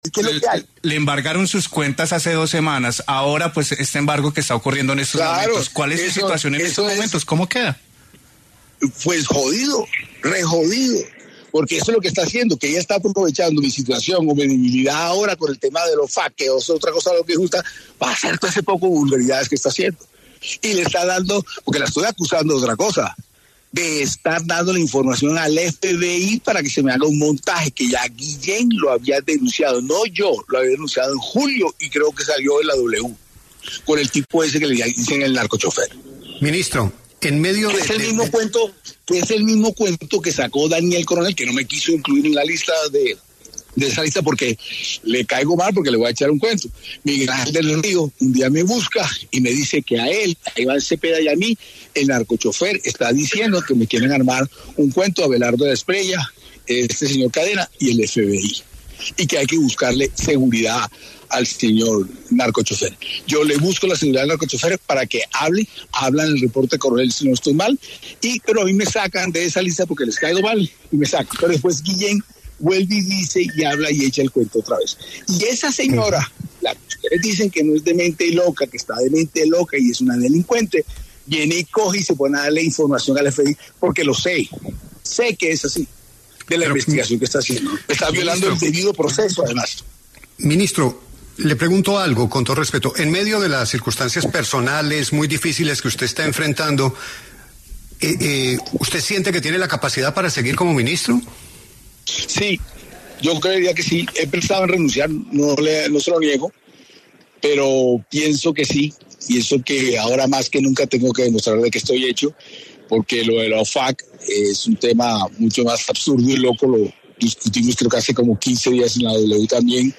En diálogo con La W, el ministro del Interior, Armando Benedetti, aseguró estar cansado de las investigaciones a él y su círculo familiar.